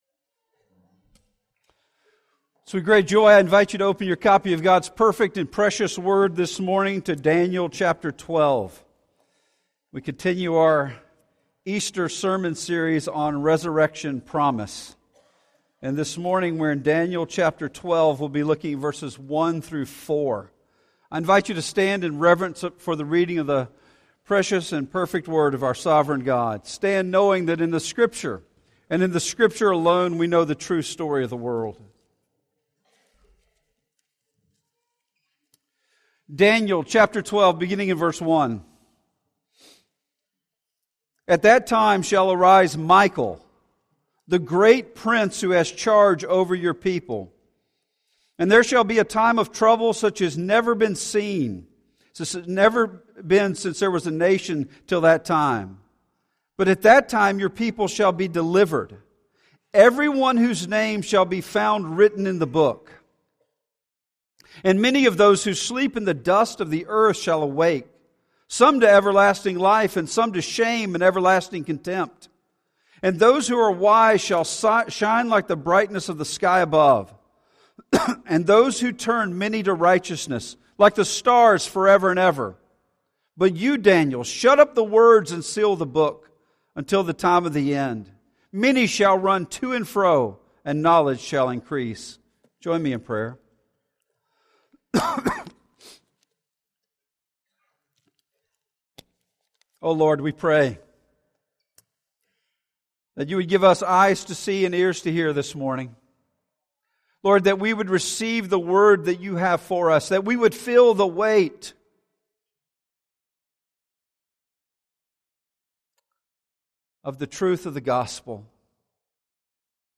Today's sermon from our Resurrection Promise! series illustrates that all of the Bible points toward resurrection.